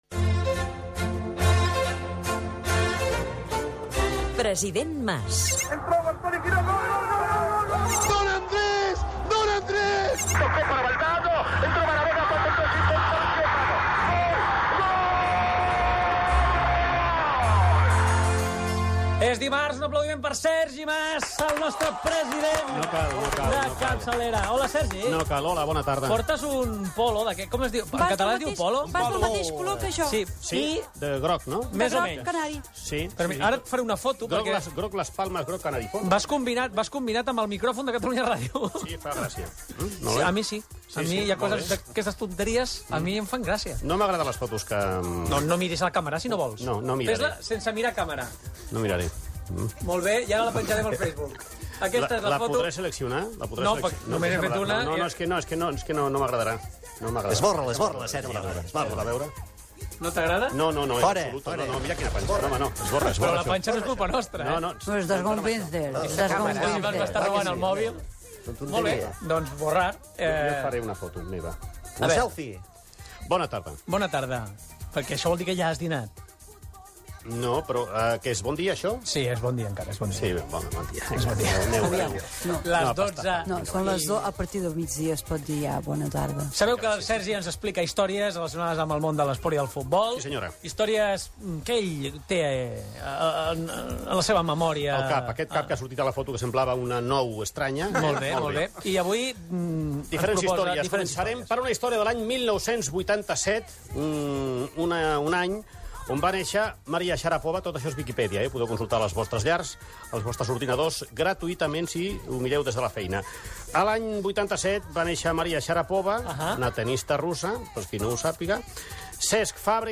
Gènere radiofònic Info-entreteniment